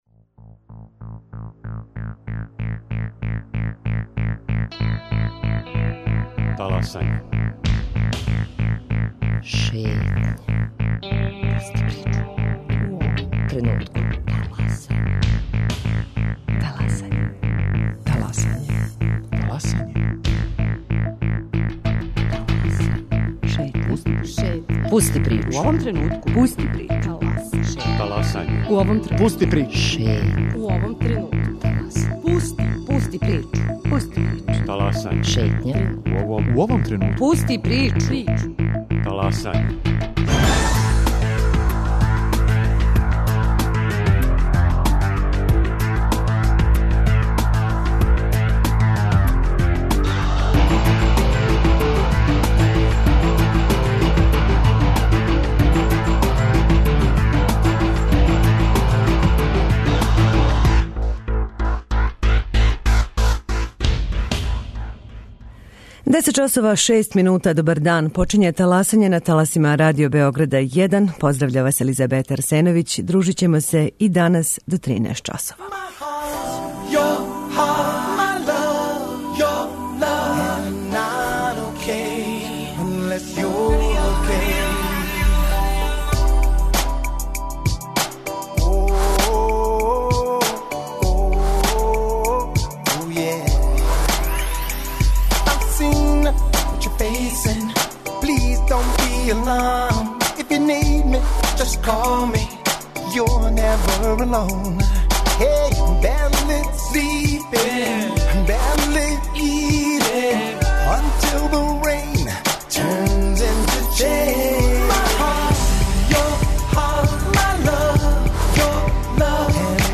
Радио Београд 1, 10.05